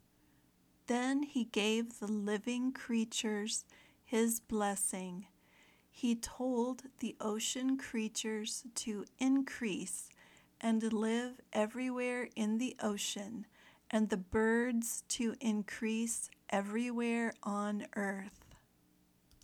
If you are learning American English, imitate her pronunciation the best you can.